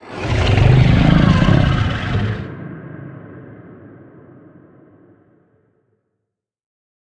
Sfx_creature_bruteshark_callout_03.ogg